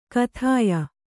♪ kathāya